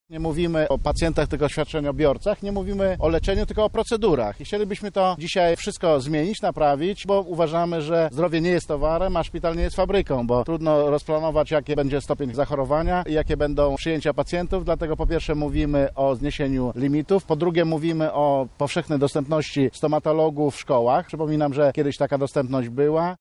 – mówi Jacek Czerniak, poseł SLD